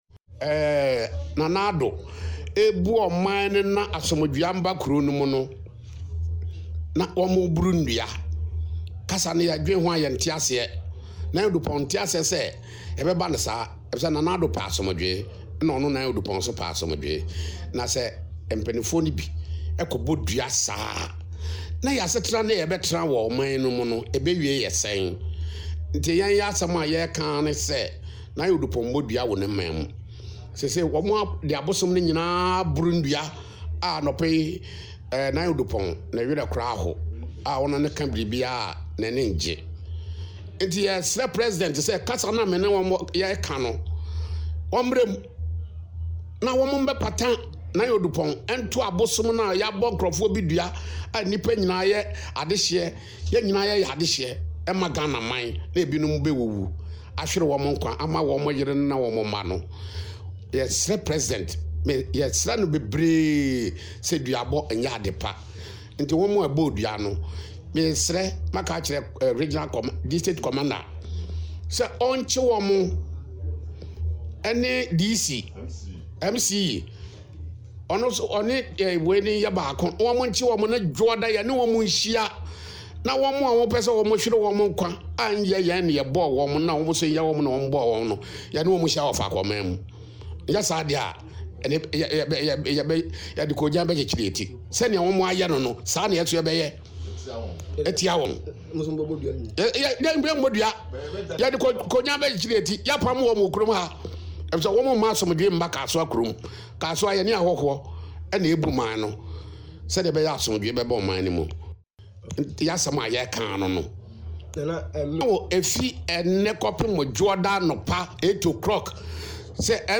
Listen to the chief